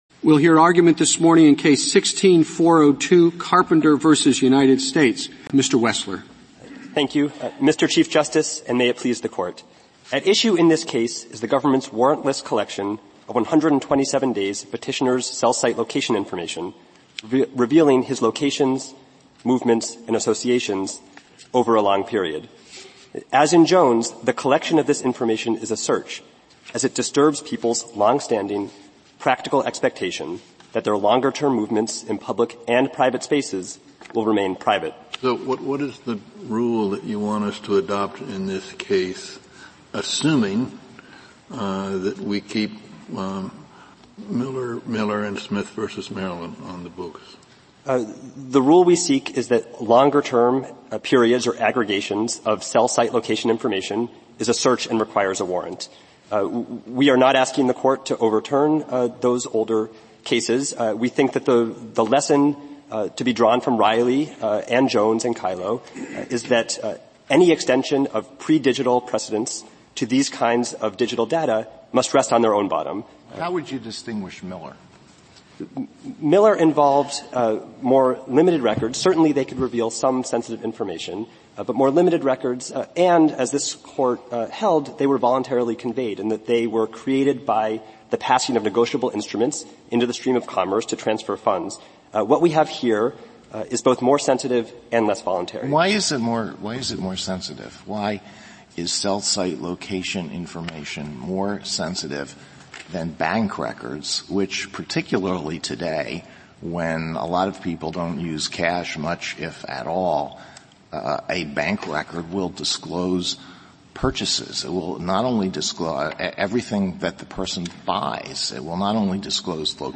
Excerpt From Oral Argument in Carpenter v. United States